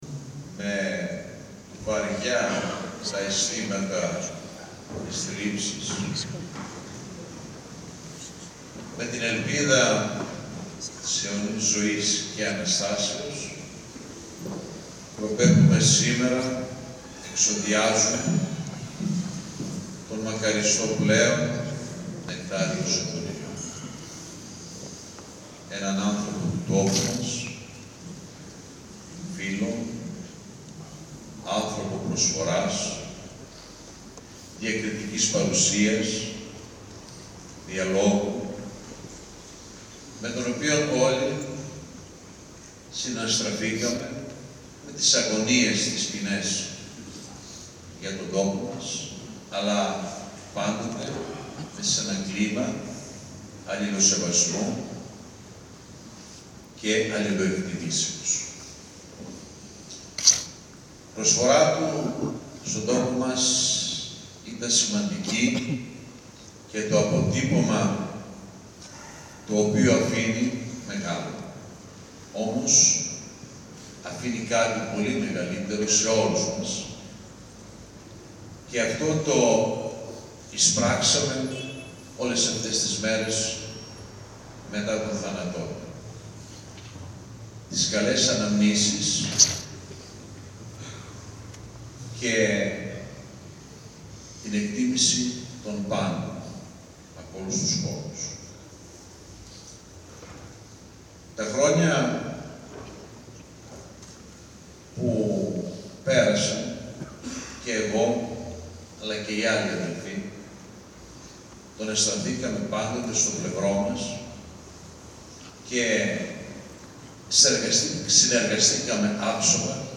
Ο επικήδειος λόγος του Σεβασμιοτάτου Μητροπολίτου Ρόδου κ. Κύριλλου